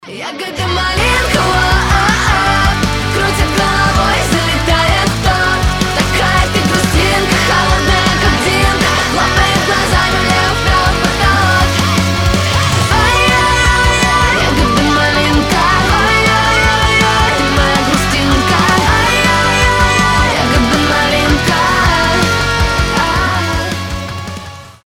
громкие
веселые
Cover
Pop Rock
Поп-рок кавер весёлой песни